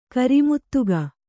♪ gharmōdaka